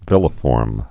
(vĭlə-fôrm)